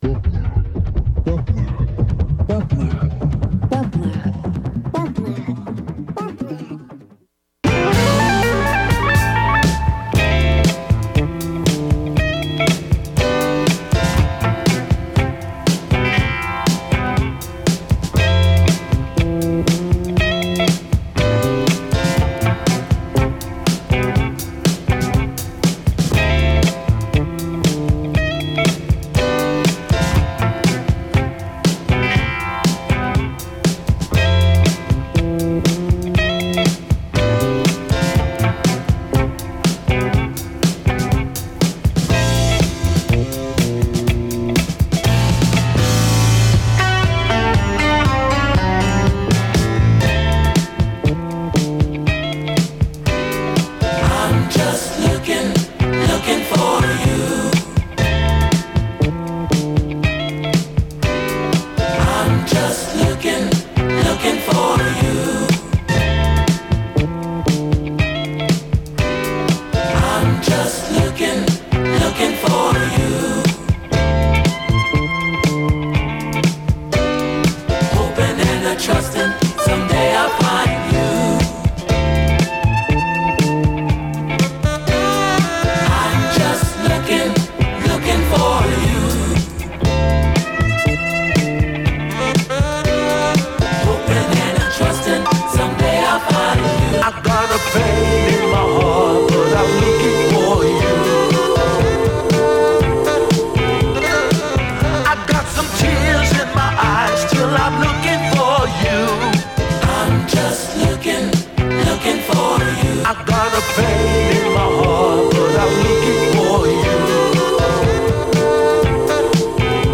Balearic Disco/House Funk/Soul International